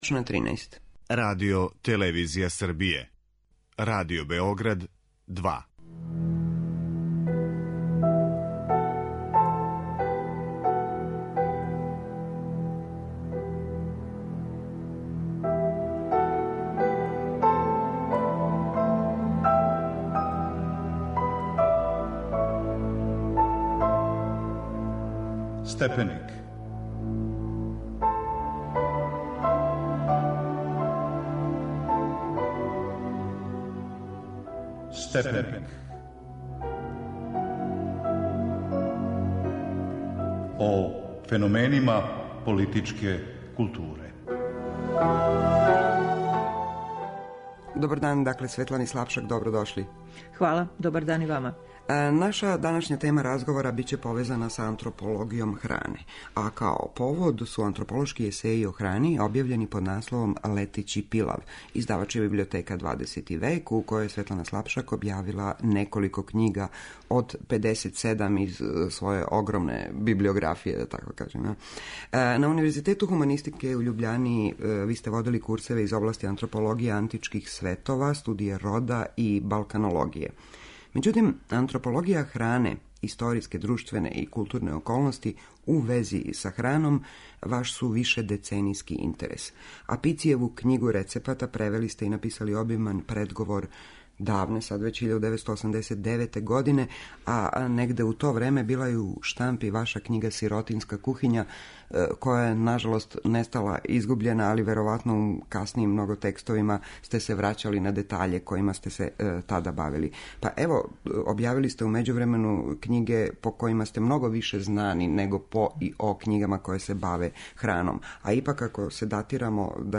Са Светланом Слапшак разговарамо о њеној збирци антрополошких есеја о храни - 'Летећи пилав'